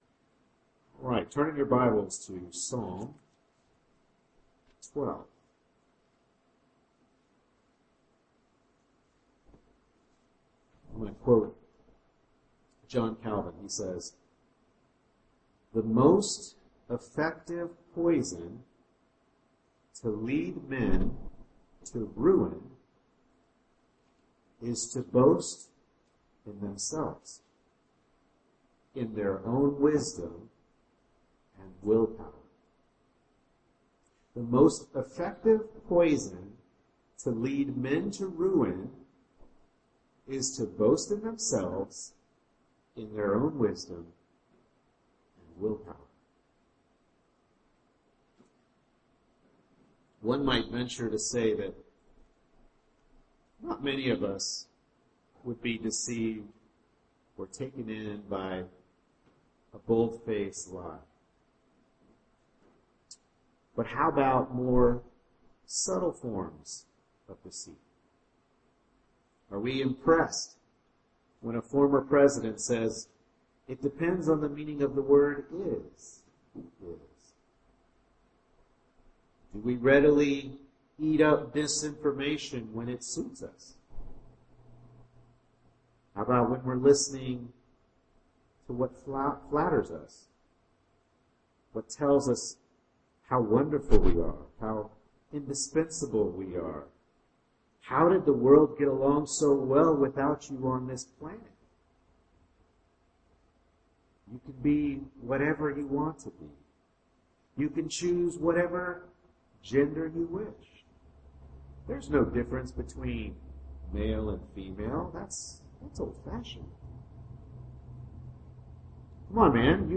Psalm 12 Service Type: Morning Worship Service Bible Text